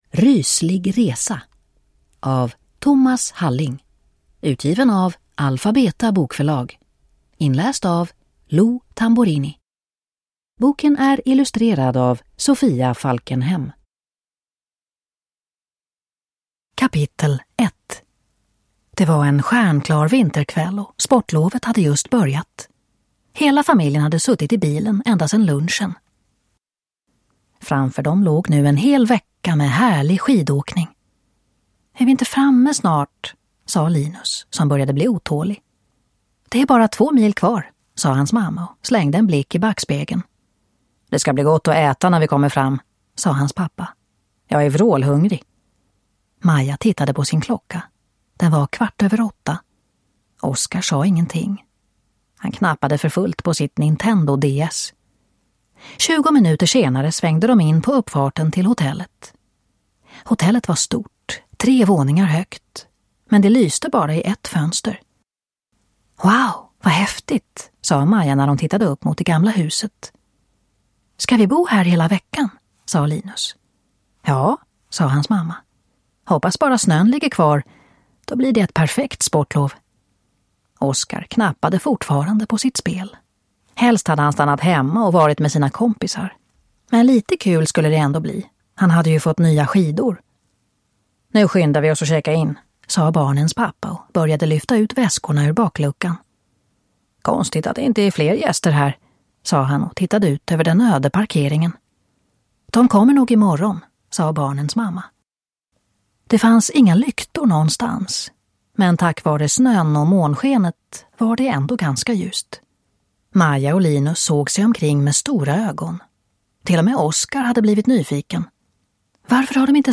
Ryslig resa – Ljudbok